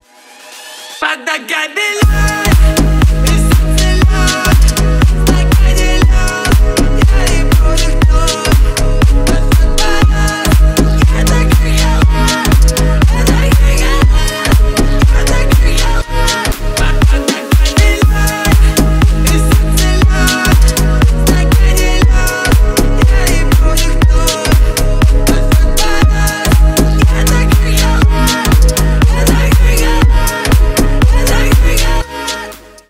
Ремикс
грустные